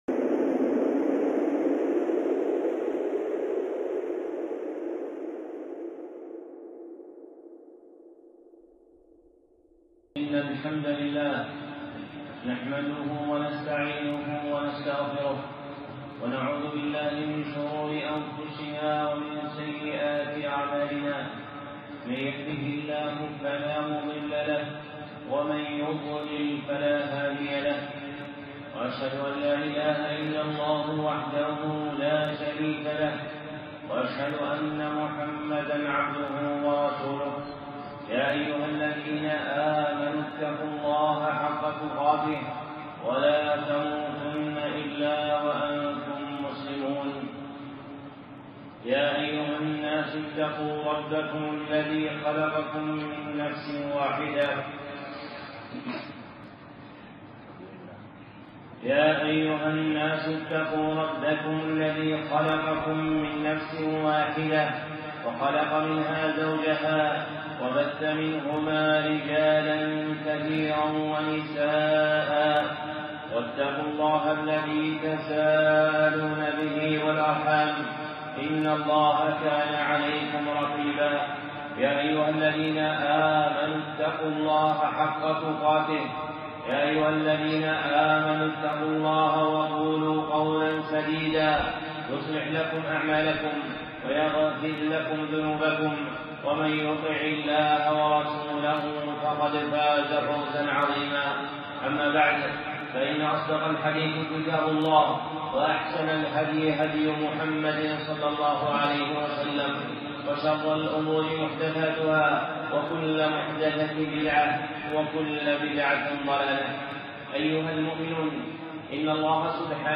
خطبة (السبيل لإقامة الحقوق اللازمة على العبيد
الخطب المنبرية